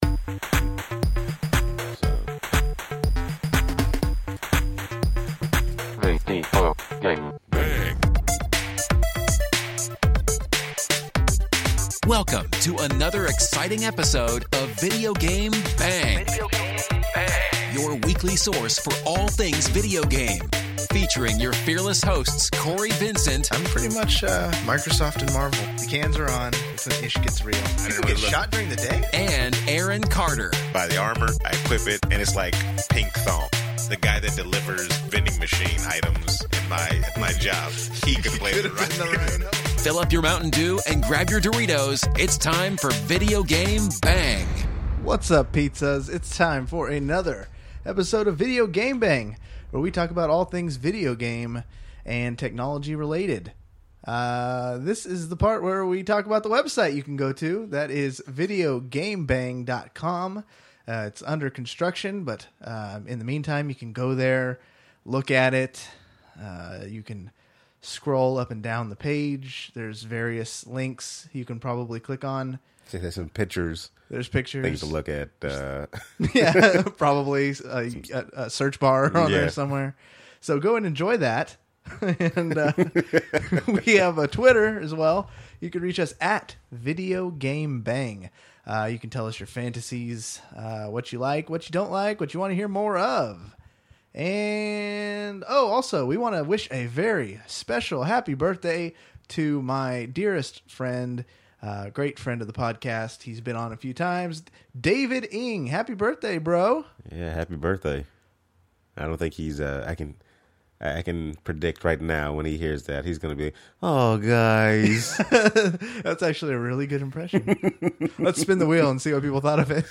We go OLD SCHOOL on this episode of the podcast, just two good friends 'bro-ing' it up! We talk a variety of gaming topics GTAV, PS4 and Xbox One. We talk some Pro- Wrestling, Some Anime, and MMA.